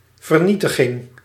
Ääntäminen
IPA: [ʁa.vaʒ]